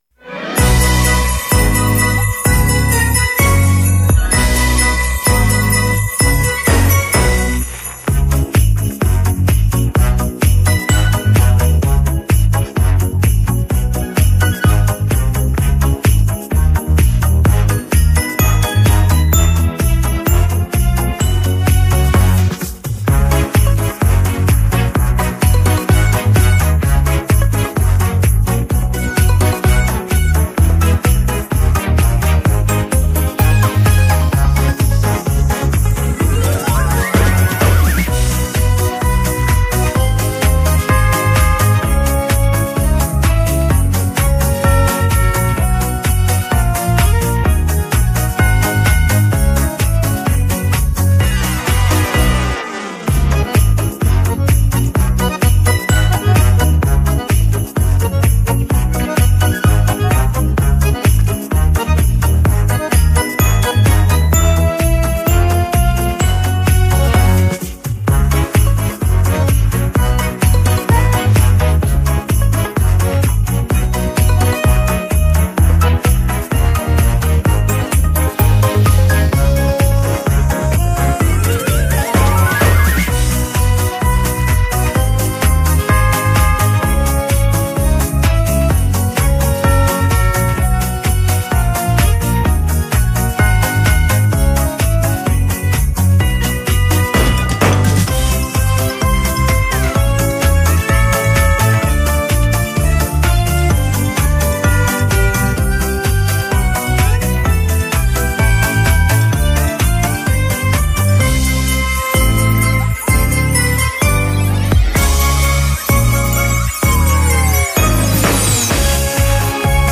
• Категория: Детские песни
Симпатичная песня, но тесситура очень низкая!